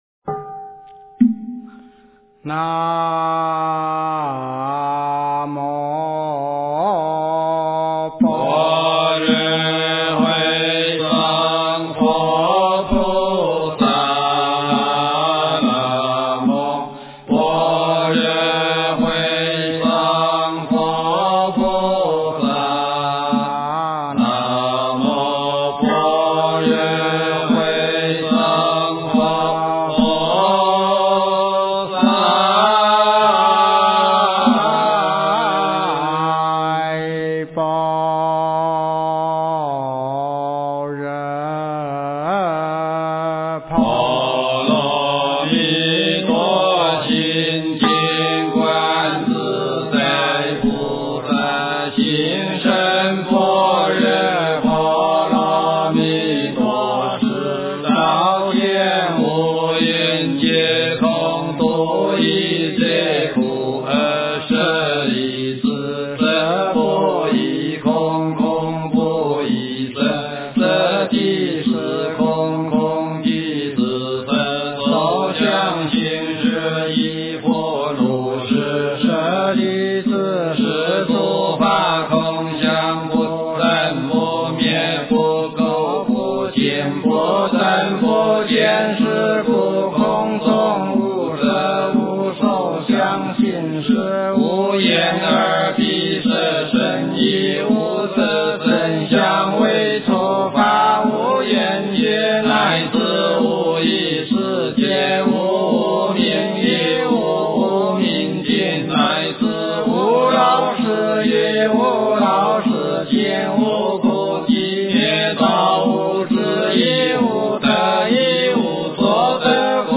般若波罗蜜多心经--新韵传音 经忏 般若波罗蜜多心经--新韵传音 点我： 标签: 佛音 经忏 佛教音乐 返回列表 上一篇： 弥陀圣号-五音调--如是我闻 下一篇： 午课--大华严寺 相关文章 观文佛号--佛光山梵呗团 观文佛号--佛光山梵呗团...